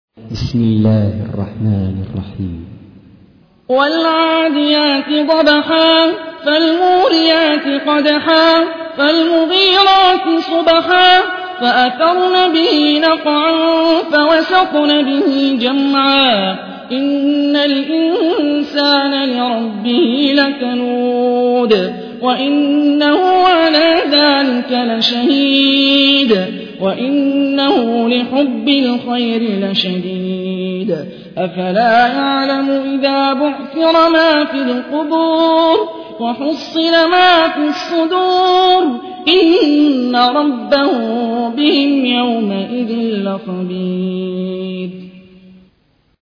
تحميل : 100. سورة العاديات / القارئ هاني الرفاعي / القرآن الكريم / موقع يا حسين